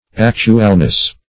Actualness \Ac"tu*al*ness\